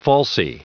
Prononciation du mot falsie en anglais (fichier audio)
Prononciation du mot : falsie